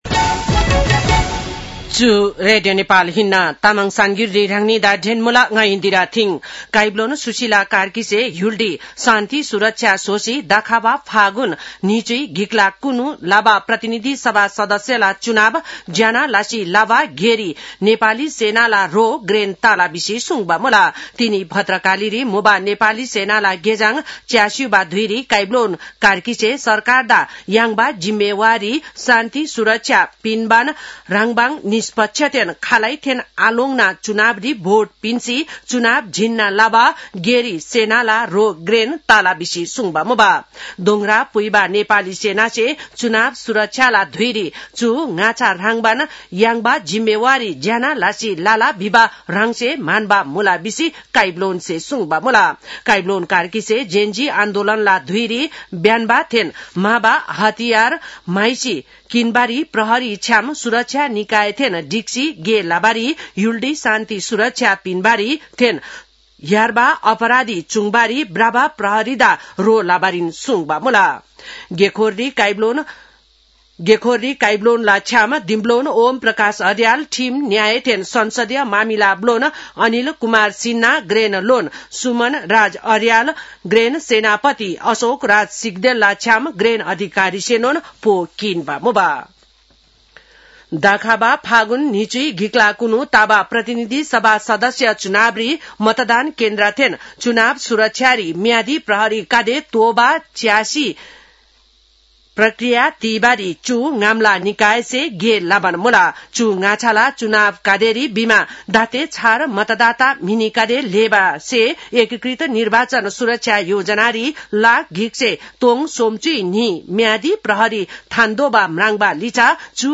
तामाङ भाषाको समाचार : १२ मंसिर , २०८२
Tamang-news-8-12.mp3